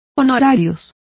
Complete with pronunciation of the translation of fee.